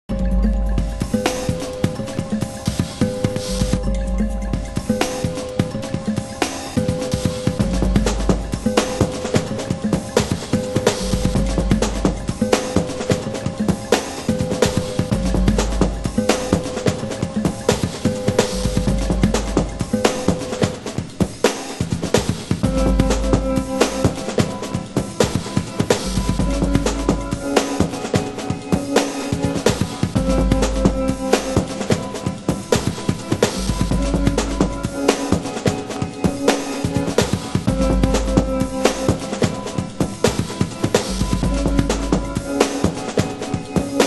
○JAZZエッセンスを感じさせる、DUB/BREAK/DRUM&BASSまで！